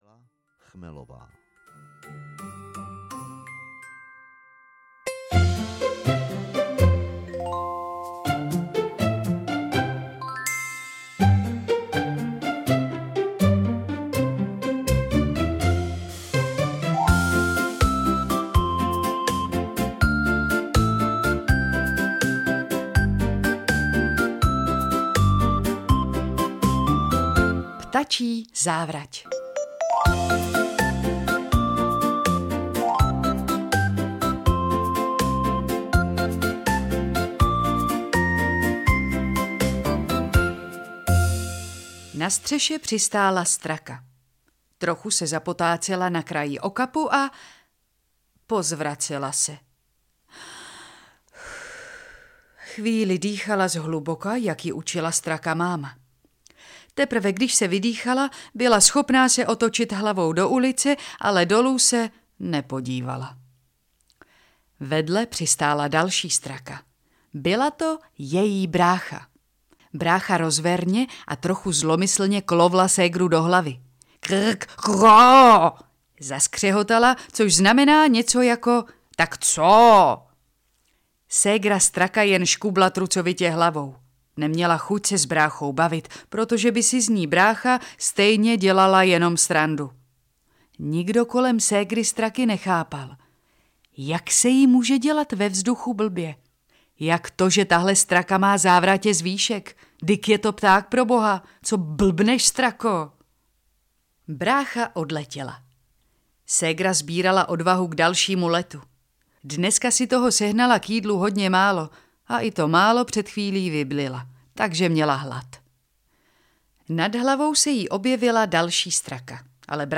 Audiokniha Chytrý osel a jiné povídky, kterou napsal Jiří Roth, je sbírka moderních pohádek a zvířecích podobenství, v níž se neobvyklí hrdinové potýkají s nečekanými překážkami, které jim nadělil osud, a ukazují, že každý má v sobě ukrytý nějaký…
Ukázka z knihy
chytry-osel-a-jine-povidky-audiokniha